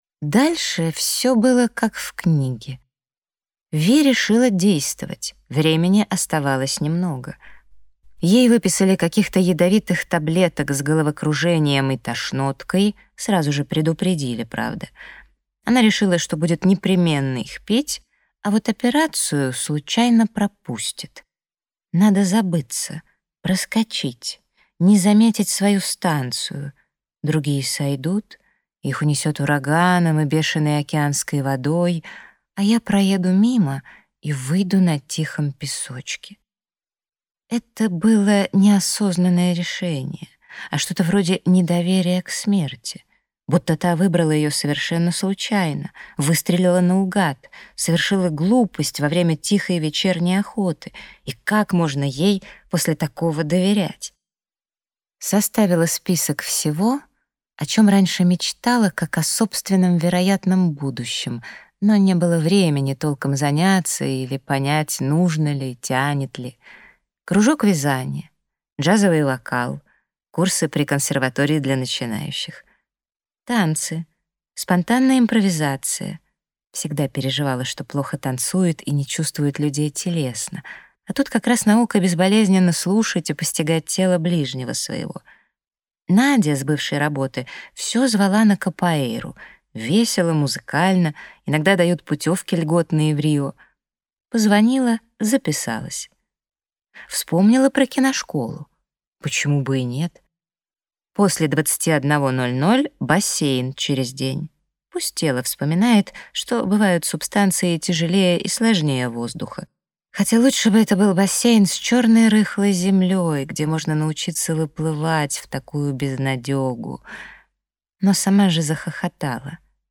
Аудиокнига Воробьиная река | Библиотека аудиокниг